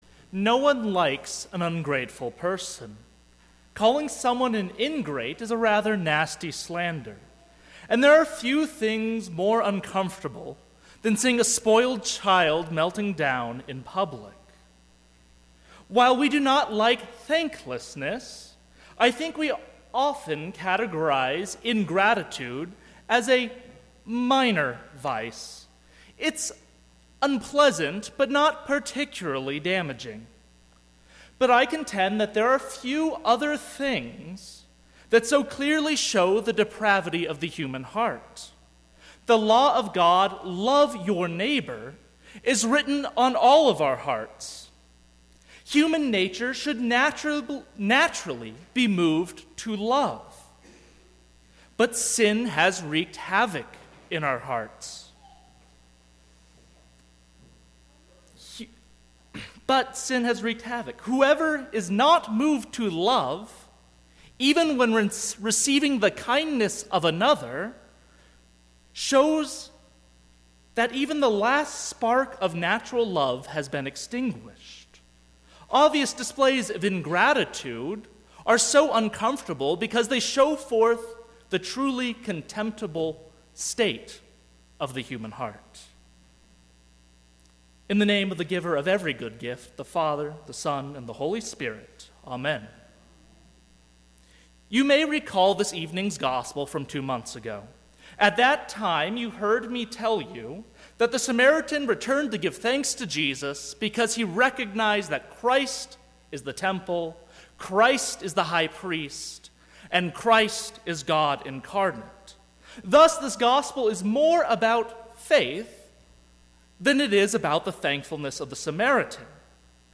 Sermon - 11/22/2017 - Wheat Ridge Lutheran Church, Wheat Ridge, Colorado
Thanksgiving Eve Service